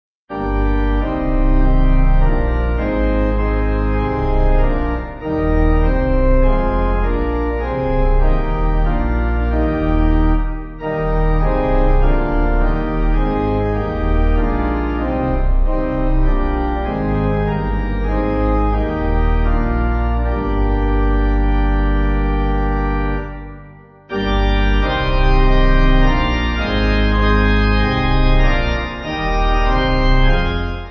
(CM)   3/Gm